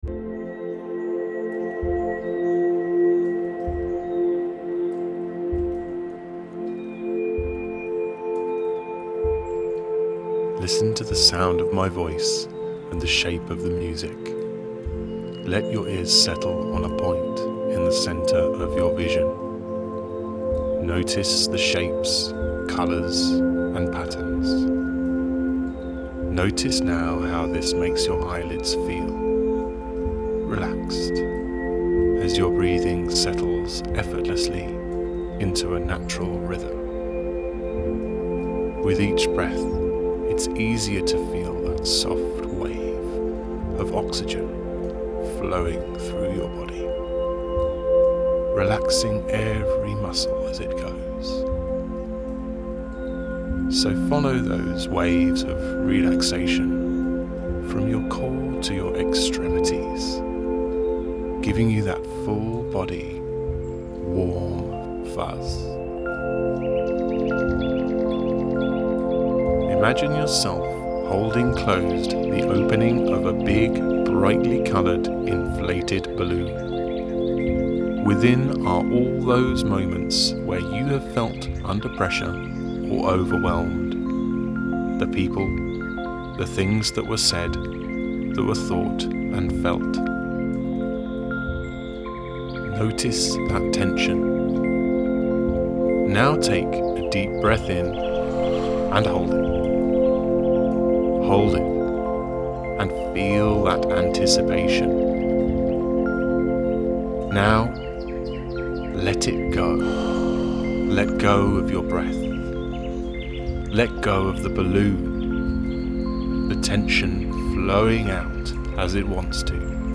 Free-decompressor-meditation-audio-download.mp3